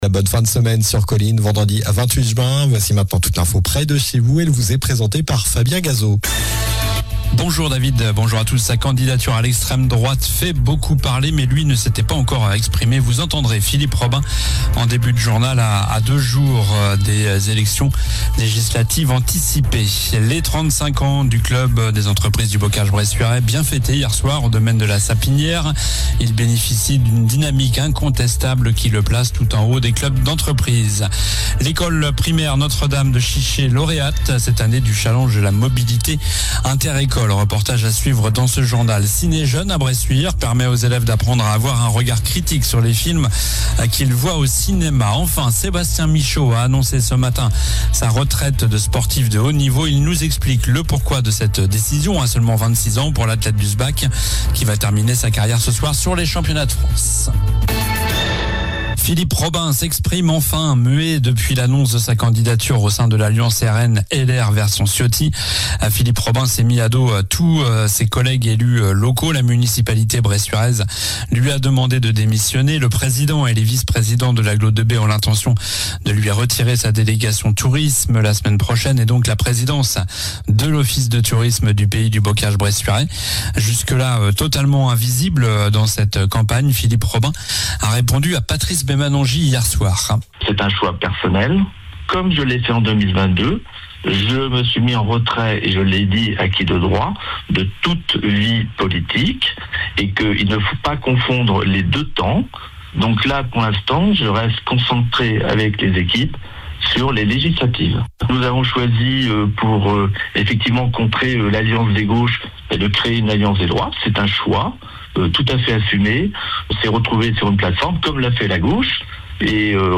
Journal du vendredi 28 juin (midi)